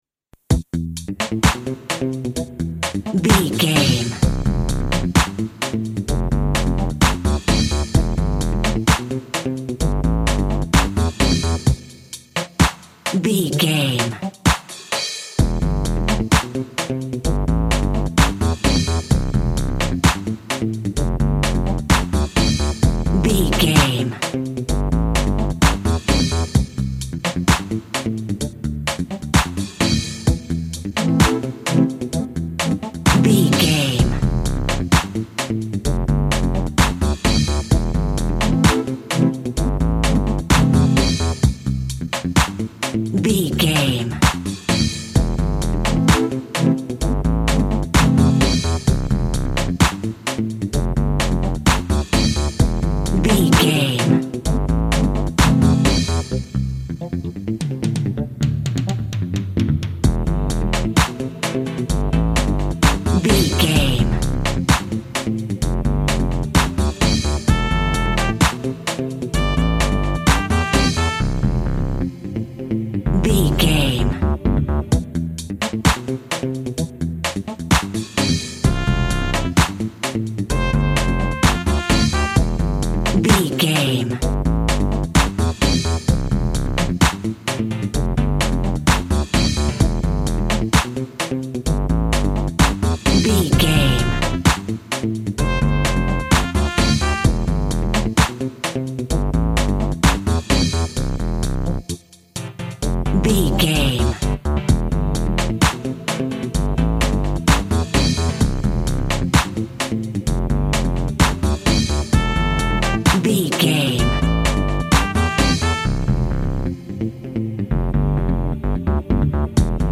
Also with small elements of Dub and Rasta music.
Aeolian/Minor
tropical
drums
bass
guitar
piano
brass
steel drum